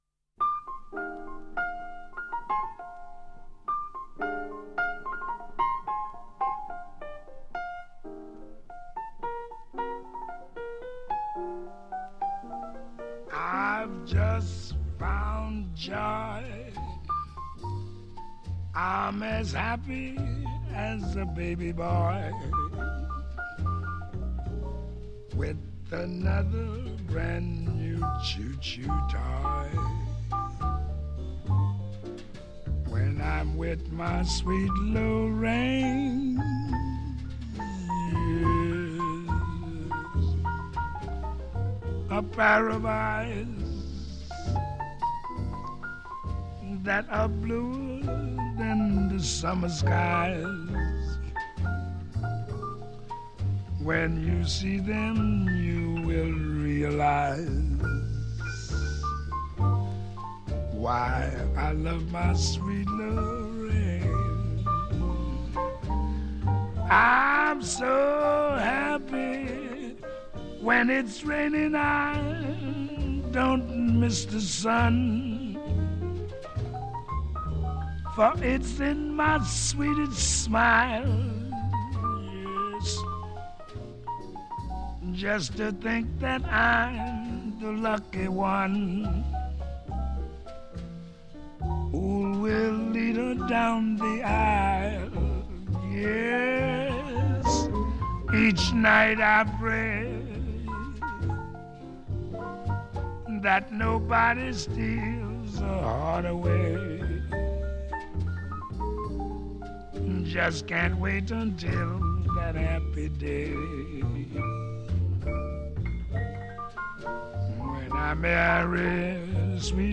Quintet
• BALLAD (JAZZ)
• VOCAL (JAZZ)
• Vocal
• Trumpet
• Piano
• Guitar
• Bass
• Drums